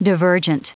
Prononciation du mot divergent en anglais (fichier audio)
Prononciation du mot : divergent